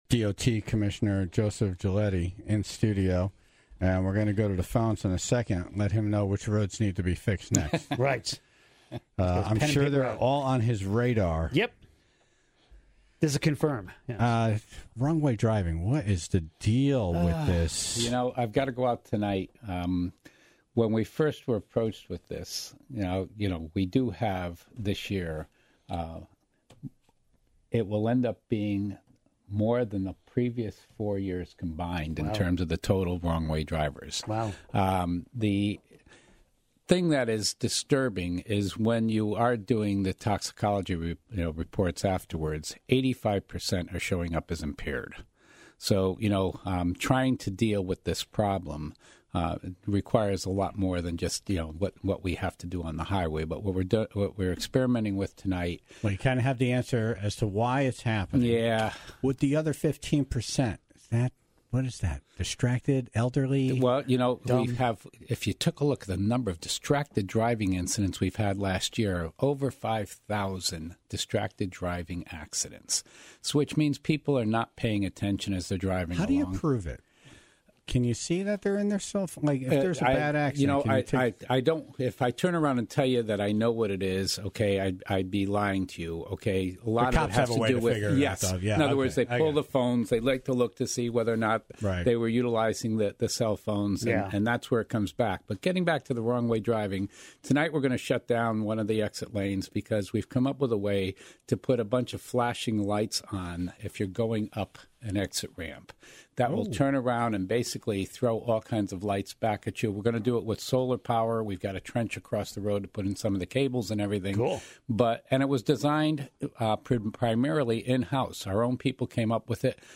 DOT Commissioner Joe Giulietti was in studio to take some calls from the Tribe and answer their questions about road repairs, winter treatments, and job opportunities.